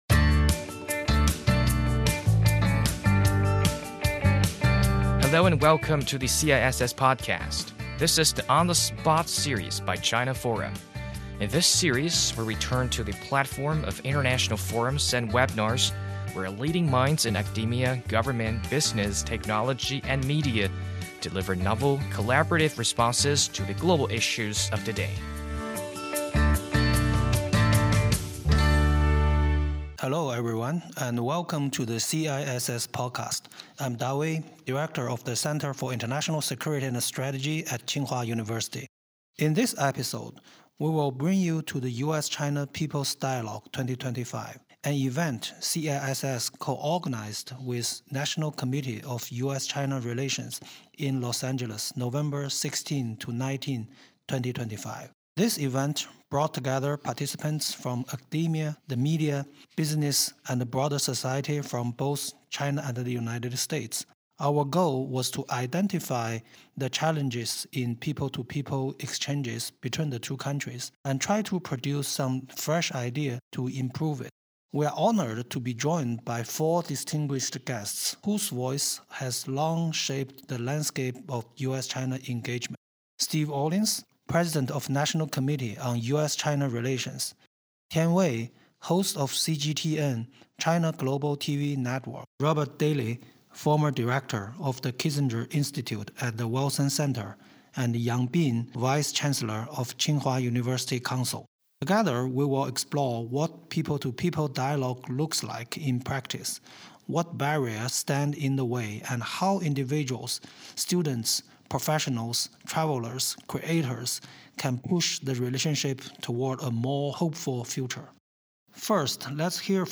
2025年11月16日至19日，清华大学战略与安全研究中心（CISS）与美国中国关系全国委员会（NCUSCR）联合主办的“中美人民交流对话会”在洛杉矶顺利举行。